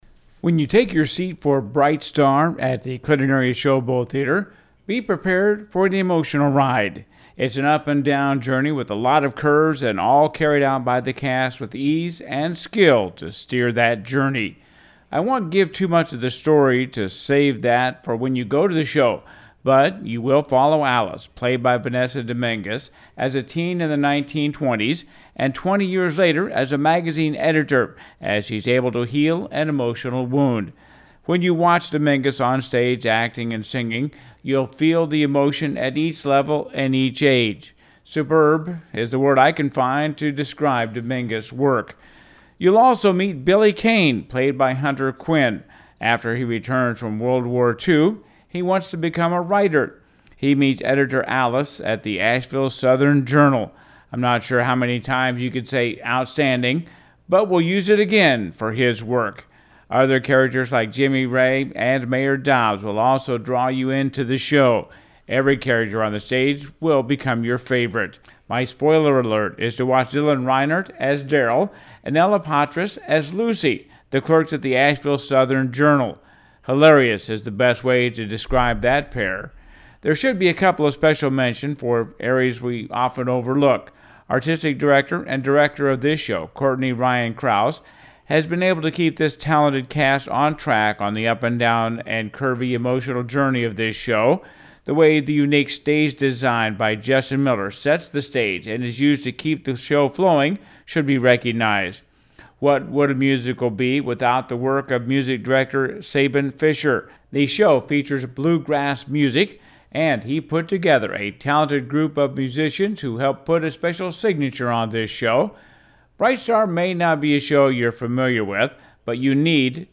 Bright Star Review